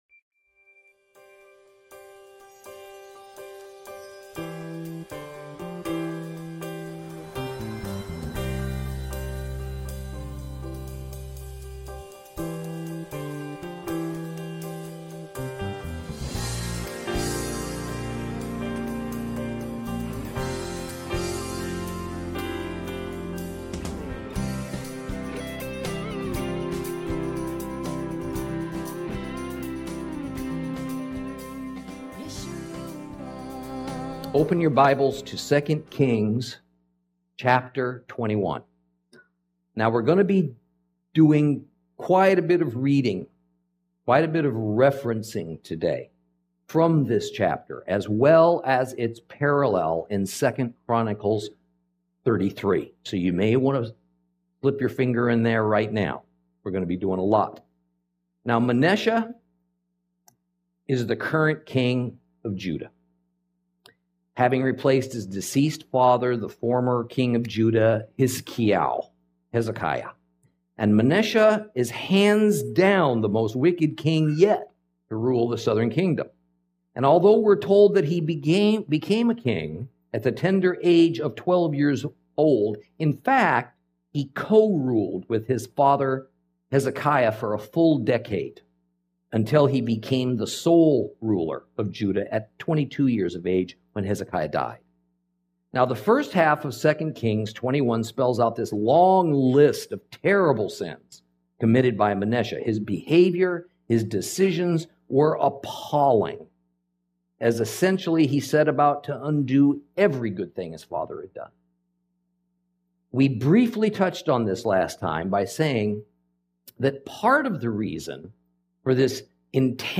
Lesson 32 Ch21 - Torah Class